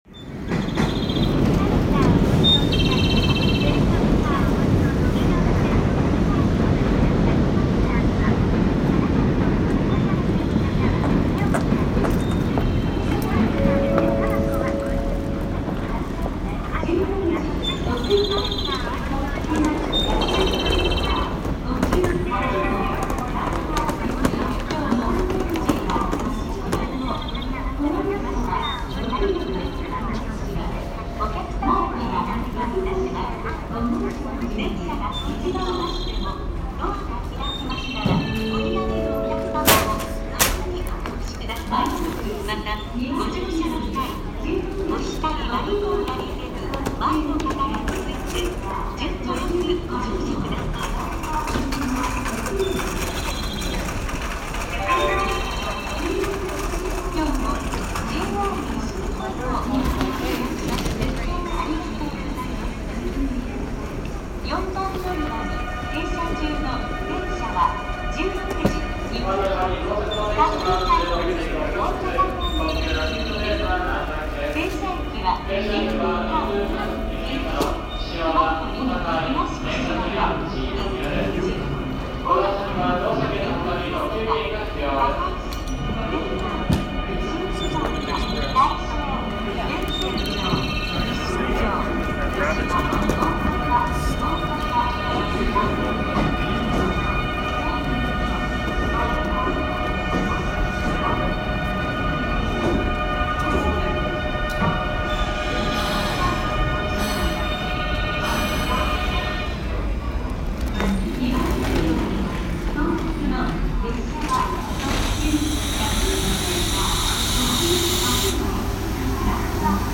Trilling Kyoto walkway
Inside Kyoto's impressive train station, we hear the end of an escalator trilling every time someone exits it, while in the meantime airport announcements punctuate the air and we hear the general bustle of a typical major station.